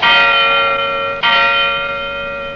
• BELL TOLL MEDIUM.wav
BELL_TOLL_MEDIUM_L9S.wav